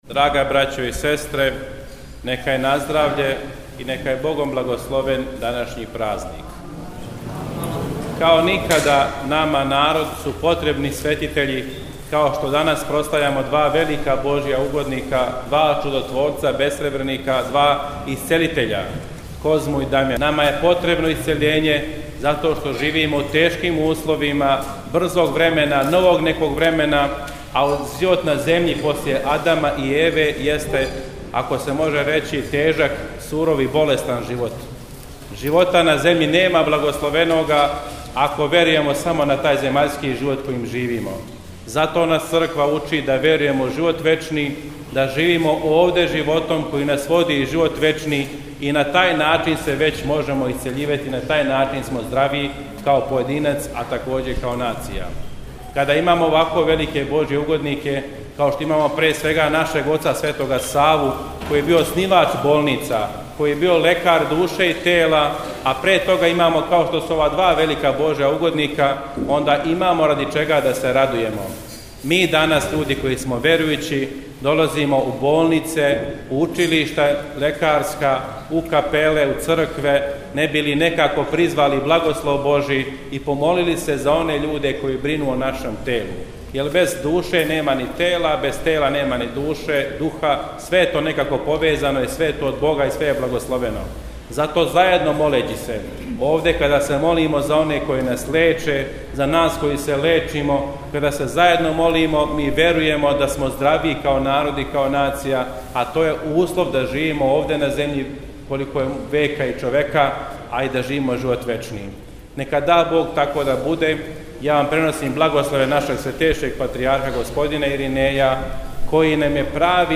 Eпископ Стефан је између осталог поучио да су нам светитељи Козма и Дамјан потребни јер нам свима треба исцељење, духовно и телесно (снимак беседе је на крају).